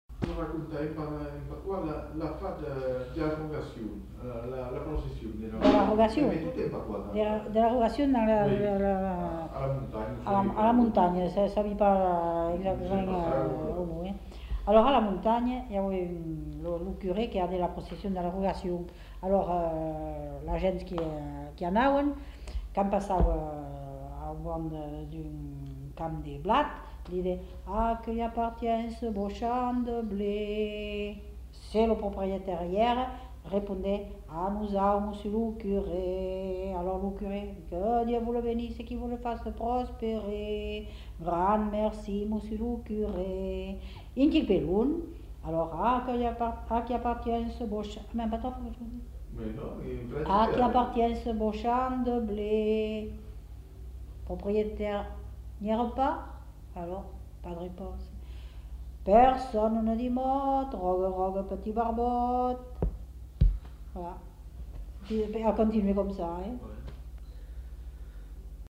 Lieu : Moulis-en-Médoc
Genre : conte-légende-récit
Effectif : 1
Type de voix : voix de femme
Production du son : parlé